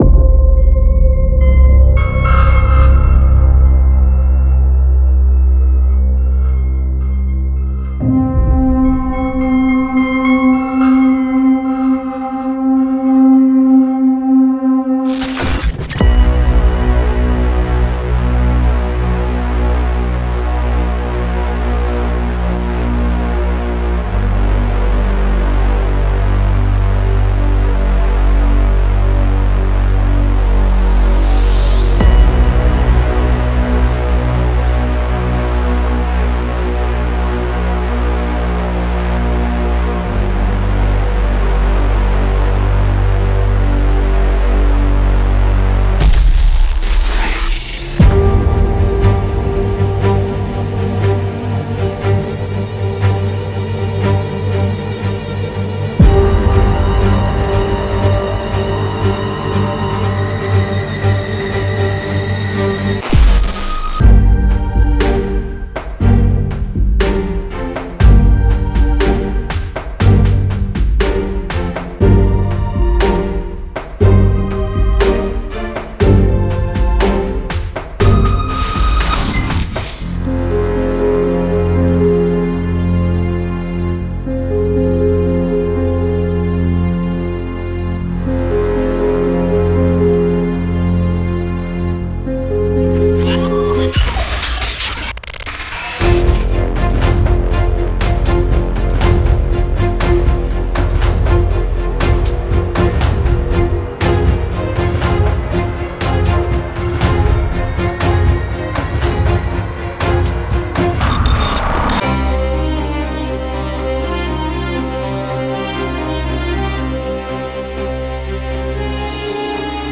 デモサウンドはコチラ↓
Genre:Filmscore
60 Cinematic Melodic Phrases